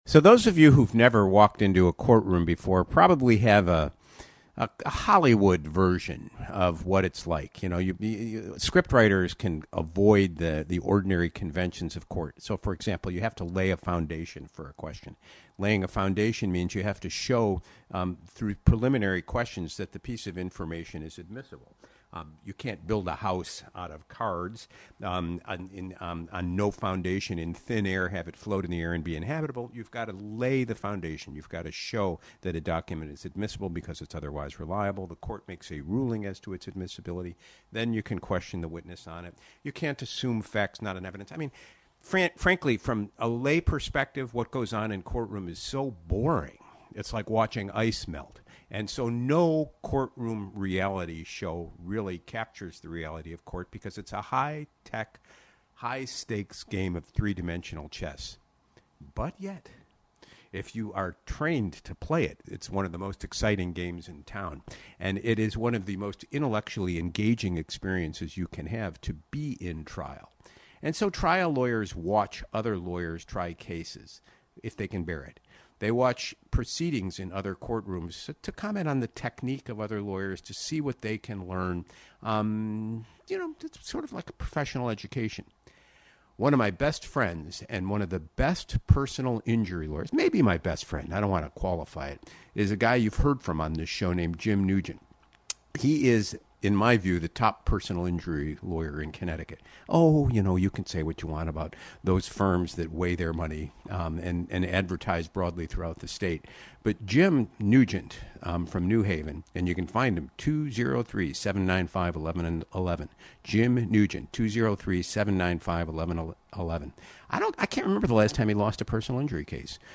which drew an interesting phone call from a listener in New Haven (24:03).